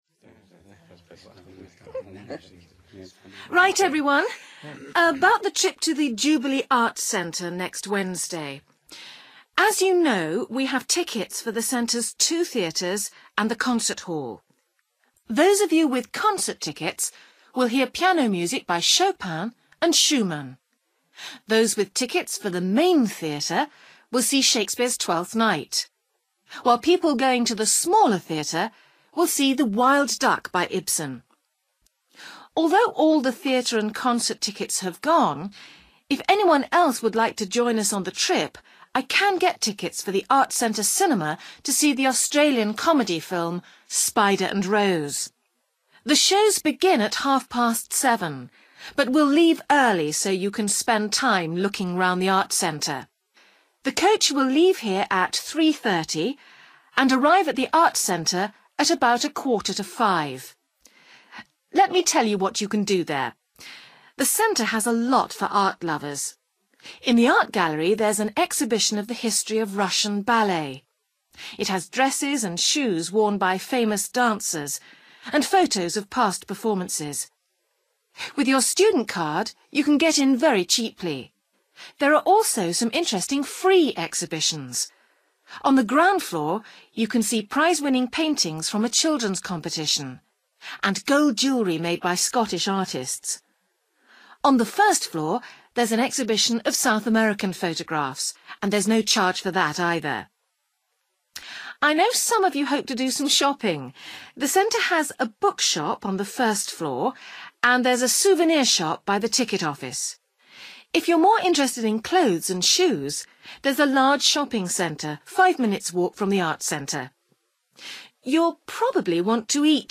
You will hear someone talking to a group of students about a visit to an Arts Centre.